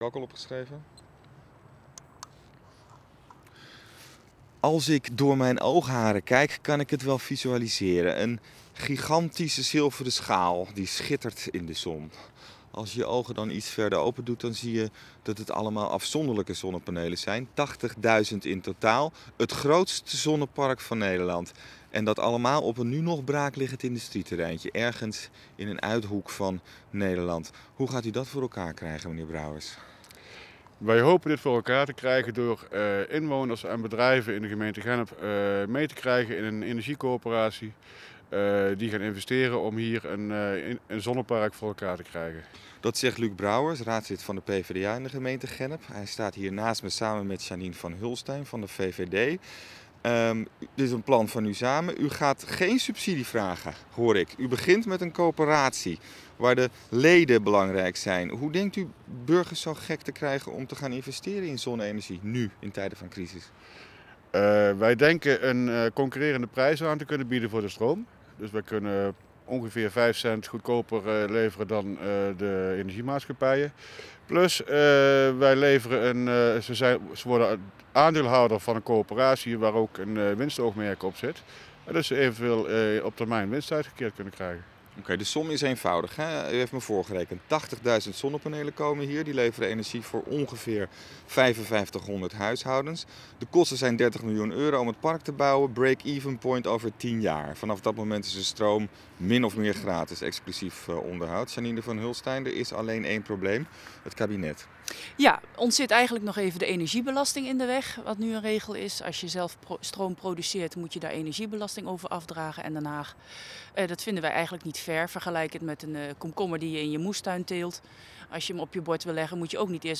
VVD Gennep - Interview met KRO Radio 1 over initiatief ZonneBrem